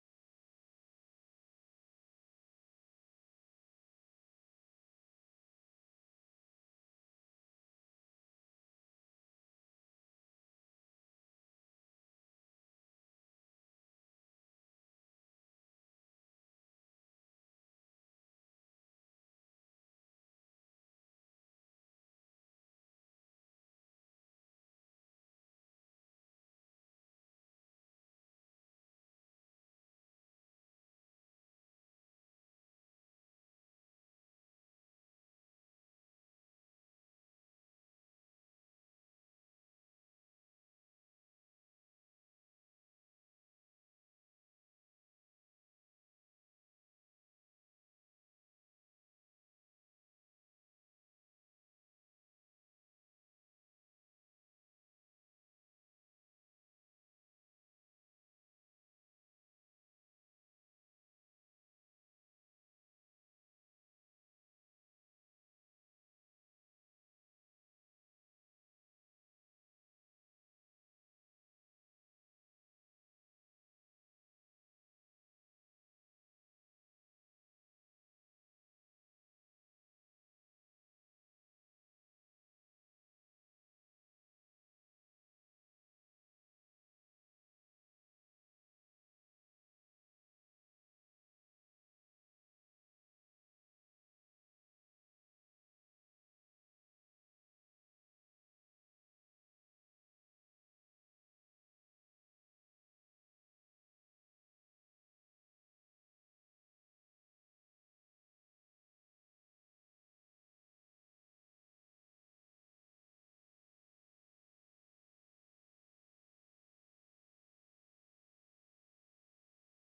Tydzień ulgi - konferencja prasowa.mp3